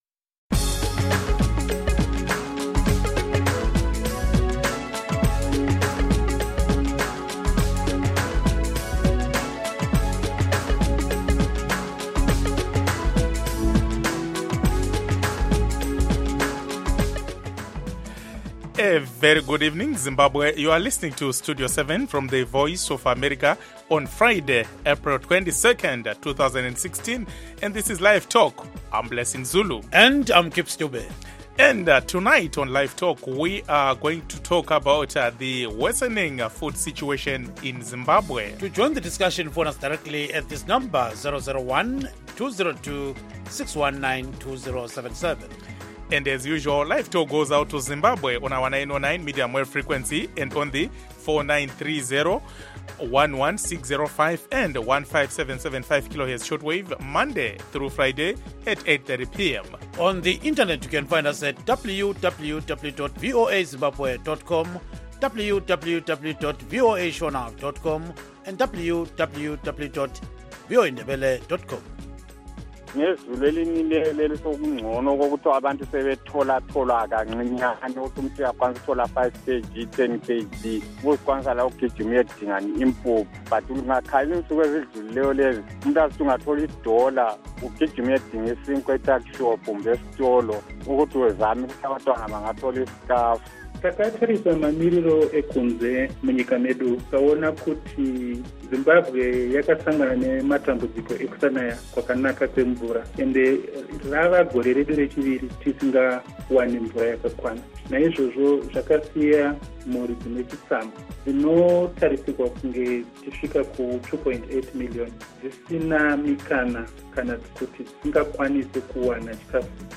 Zimbabweans living outside the country who cannot receive our broadcast signals can now listen to and participate in LiveTalk in real time.